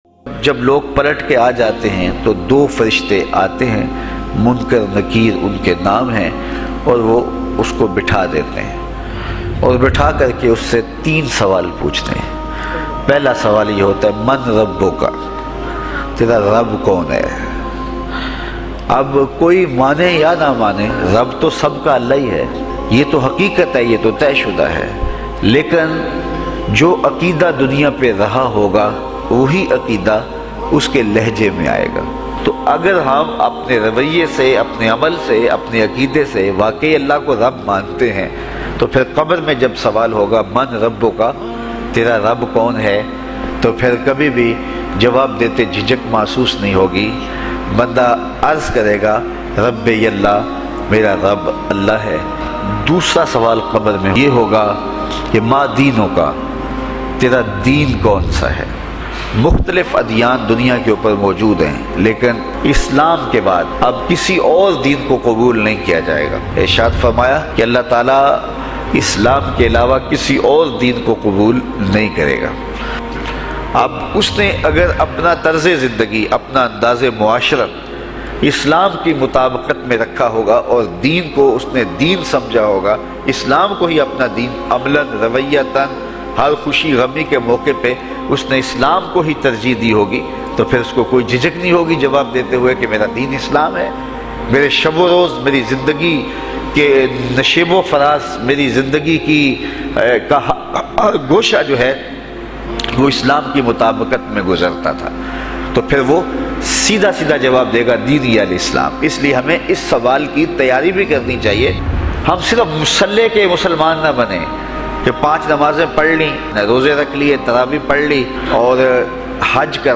Qabar me puche jane wale sawal bayan mp3
Qabar ma poxhy jany wly sawalat islamicdb bayan SM.mp3